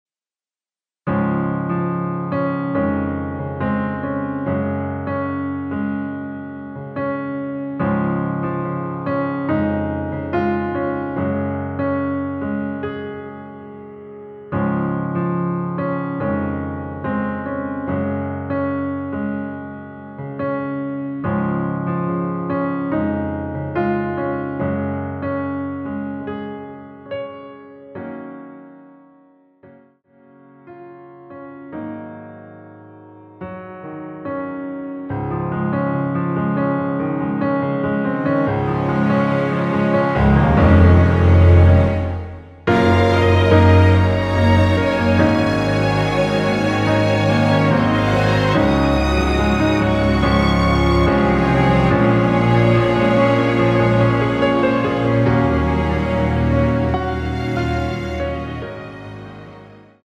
Db
◈ 곡명 옆 (-1)은 반음 내림, (+1)은 반음 올림 입니다.
앞부분30초, 뒷부분30초씩 편집해서 올려 드리고 있습니다.
중간에 음이 끈어지고 다시 나오는 이유는